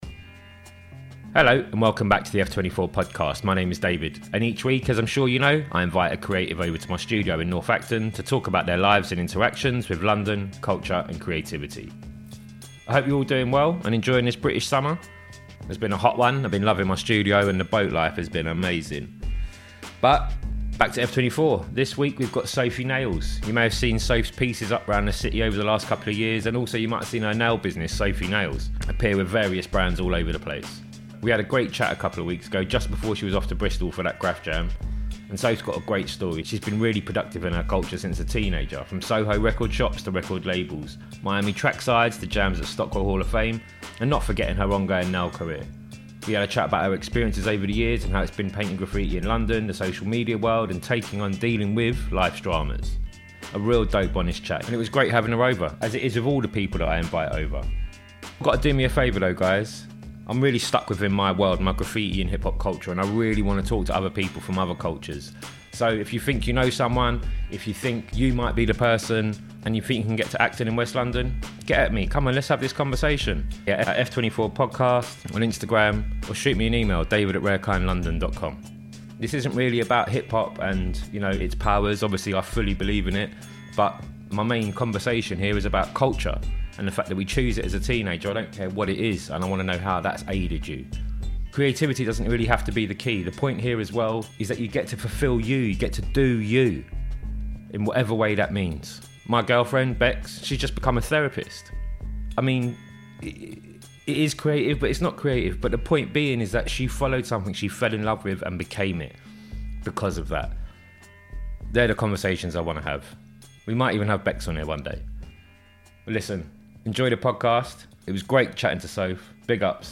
We chat about her experiences over the years and how its been painting graffiti in London, the social media world and taking on and dealing with life's dramas. A dope honest chat, it was great having her over, enjoy.